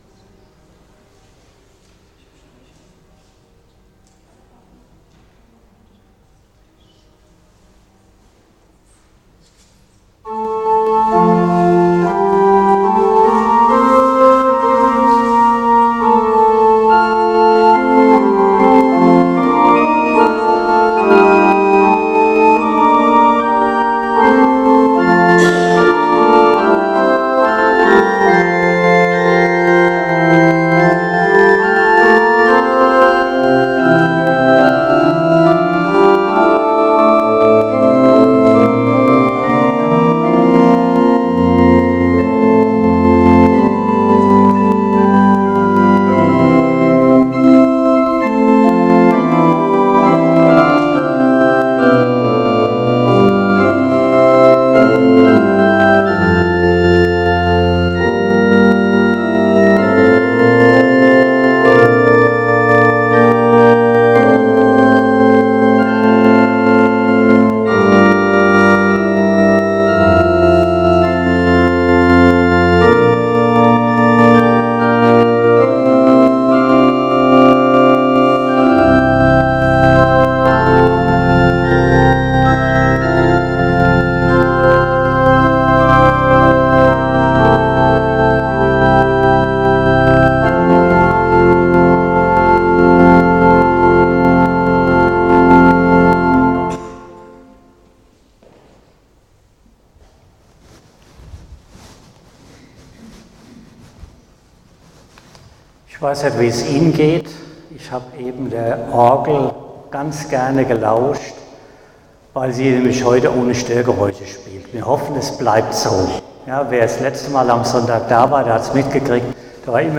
Aktuelle Predigt
Gottesdienst vom 25.01.2026 als Audio-Podcast Liebe Gemeinde, herzliche Einladung zum Gottesdienst vom 25. Januar 2026 in der Martinskirche Nierstein als Audio-Podcast.